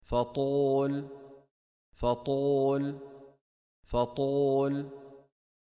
c ـــ Blanda dess ljud med en del av ljudet av bokstaven (wāw — و), som i: